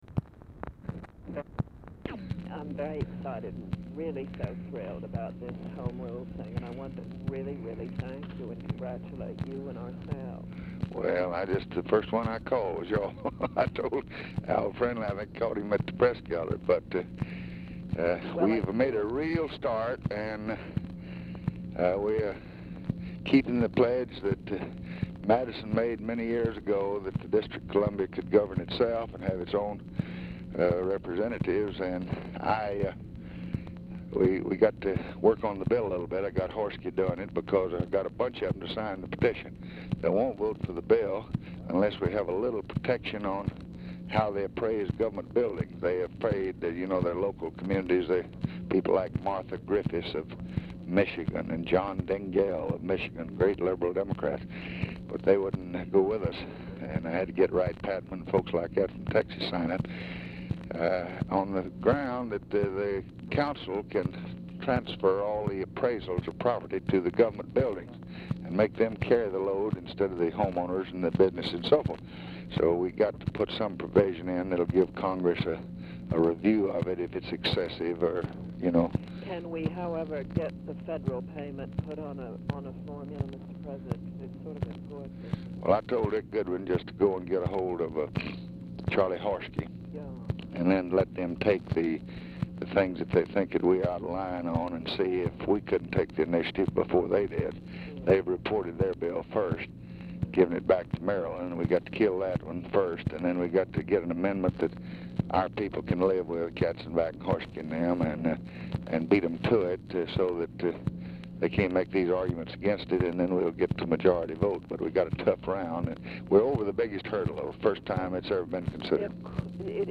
RECORDING STARTS AFTER CONVERSATION HAS BEGUN; GRAHAM IS DIFFICULT TO HEAR
Format Dictation belt
Specific Item Type Telephone conversation